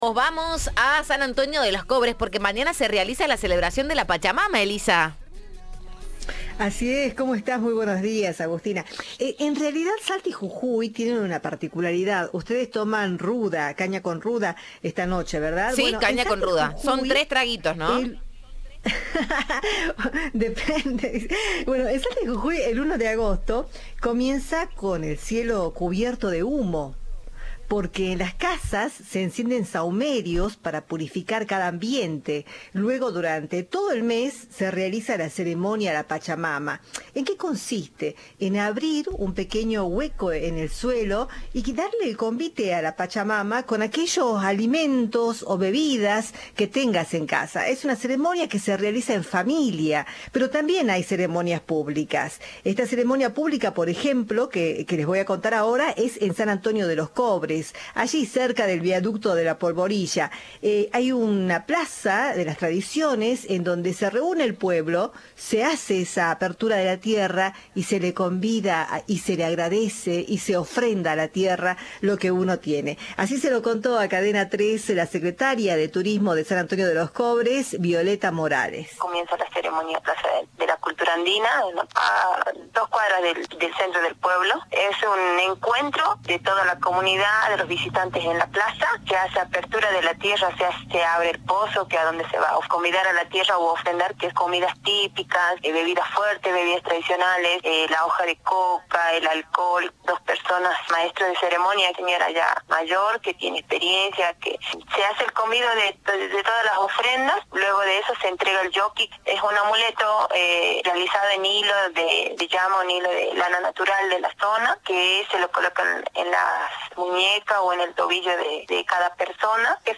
En diálogo con Cadena 3, la secretaria de turismo, Violeta Morales, contó que la ceremonia consiste en abrir un hueco en el suelo y arrojar todo tipo de ofrendas.